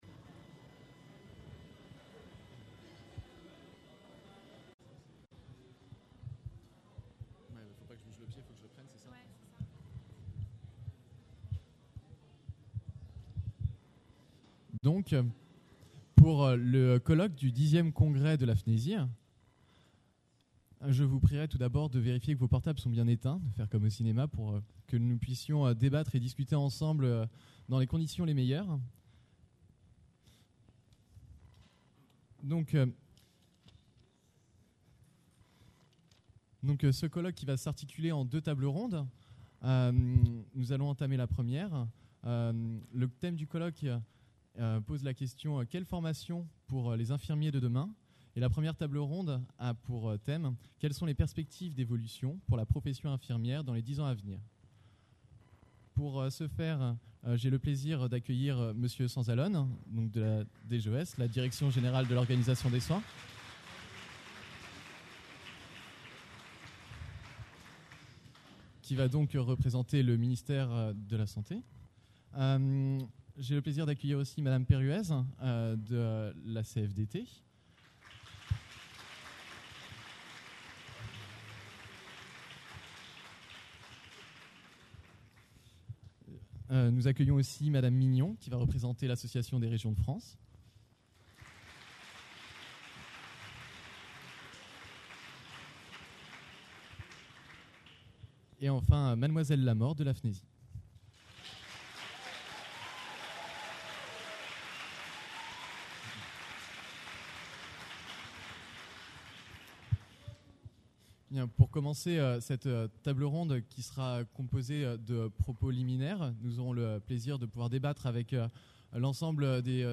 Conférence enregistrée lors 10ème Congrès National des Etudiants en Soins Infirmiers (FNESI) – Paris le 26 novembre